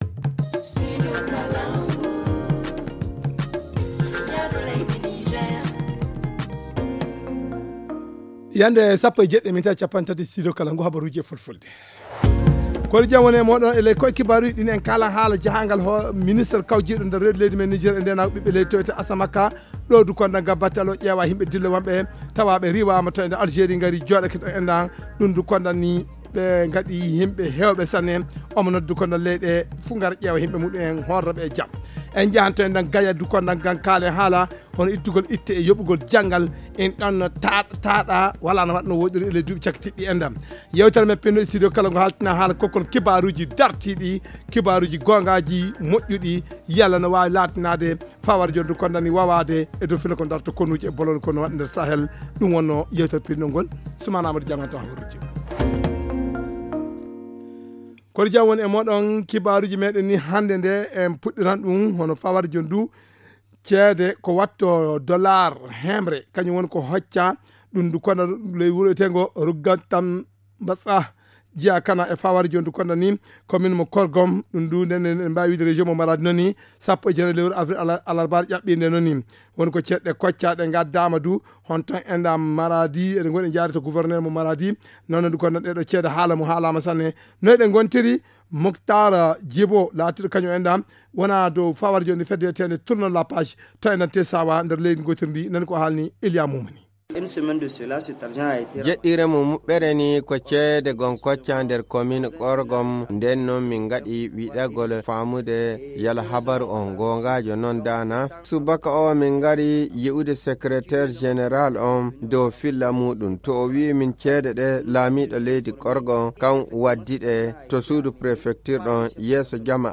Le journal du 25 avril 2023 - Studio Kalangou - Au rythme du Niger